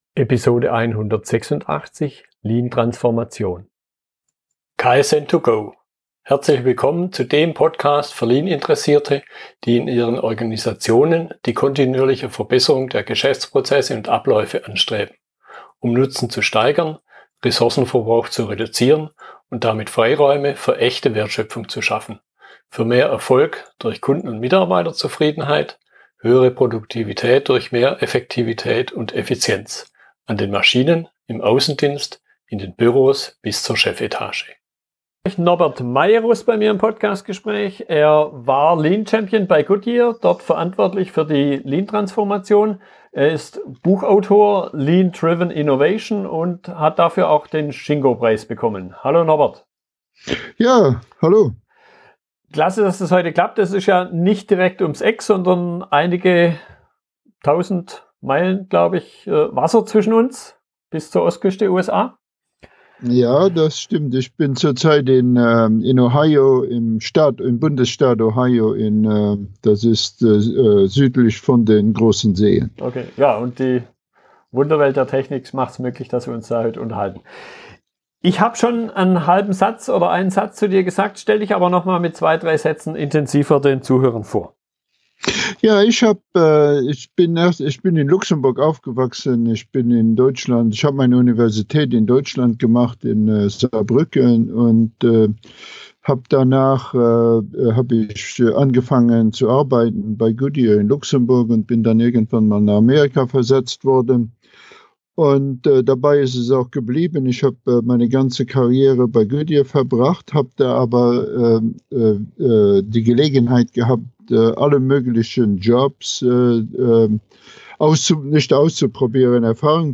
Themen und Fragestellungen aus dem Gespräch